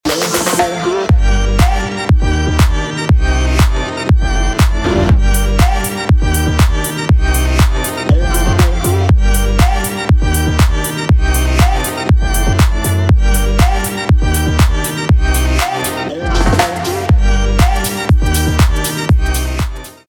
• Качество: 320, Stereo
мужской вокал
громкие
Electronic